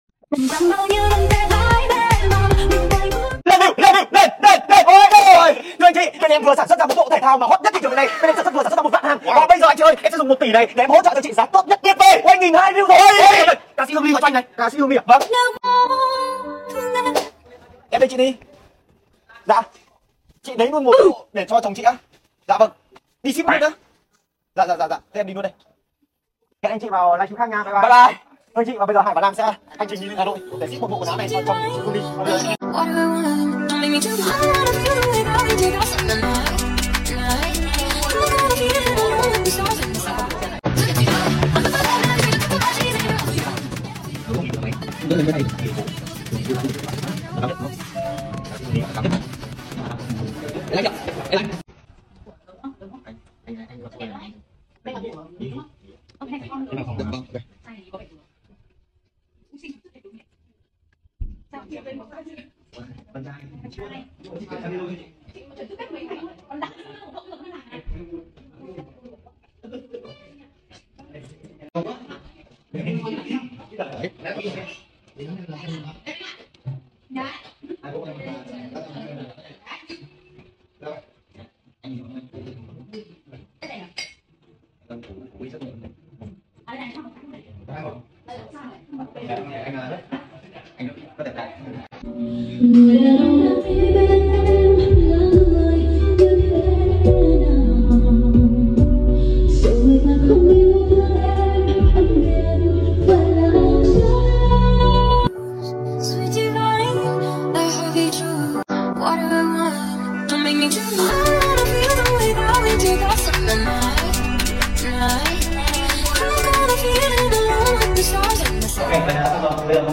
ca sỹ hát cho nghe nữa